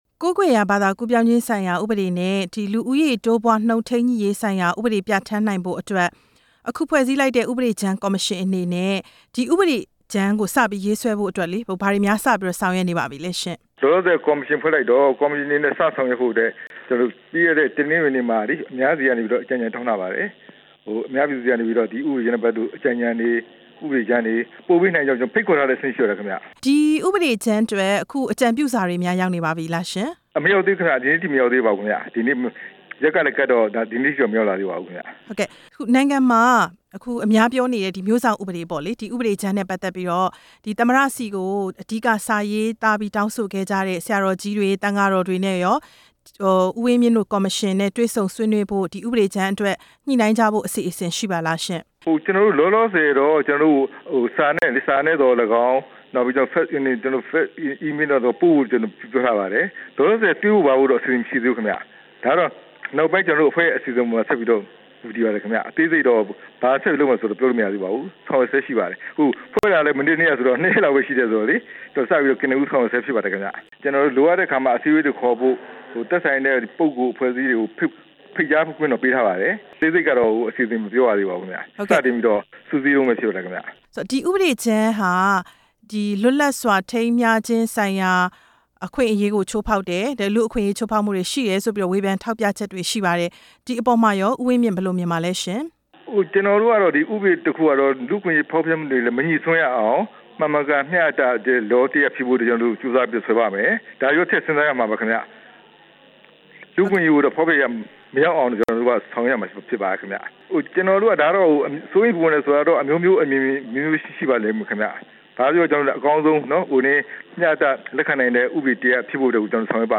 သမ္မတရဲ့ ဥပဒေကြမ်းရေးဆွဲရေး ကော်မရှင်နဲ့ ဆက်သွယ်မေးမြန်းချက်